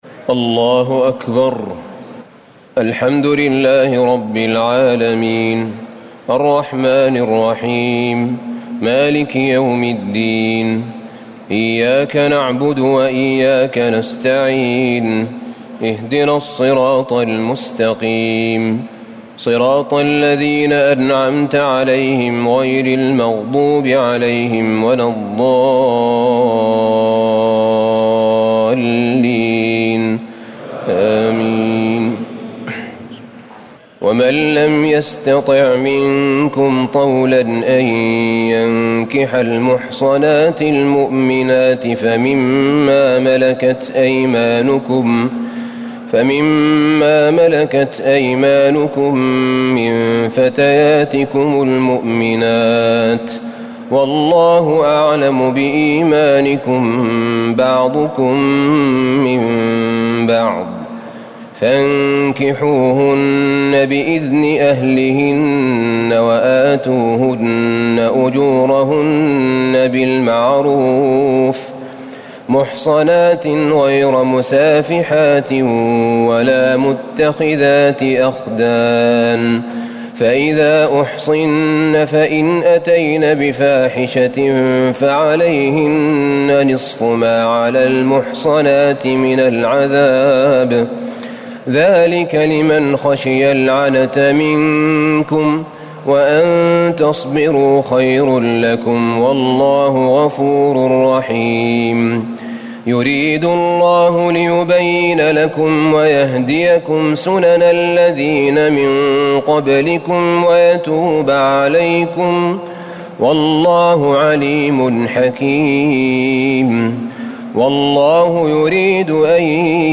تهجد ليلة 25 رمضان 1439هـ من سورة النساء (25-100) Tahajjud 25 st night Ramadan 1439H from Surah An-Nisaa > تراويح الحرم النبوي عام 1439 🕌 > التراويح - تلاوات الحرمين